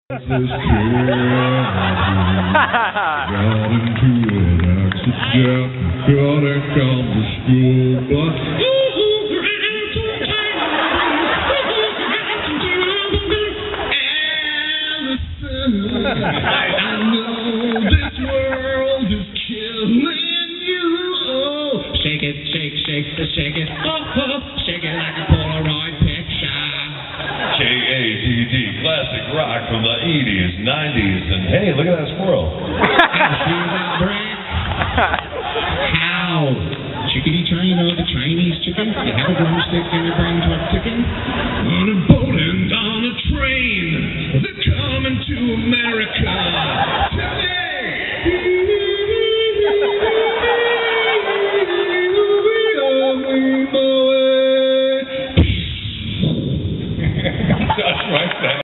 who played bass and sang.